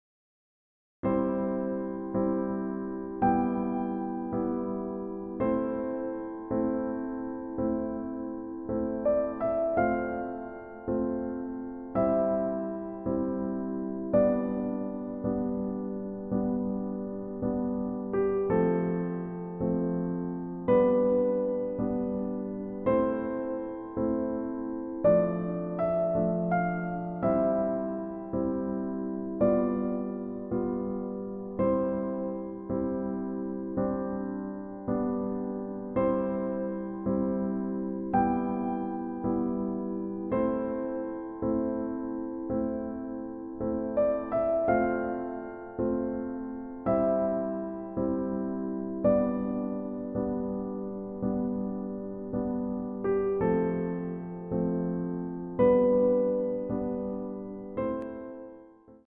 EASY MEDIUM Piano Tutorial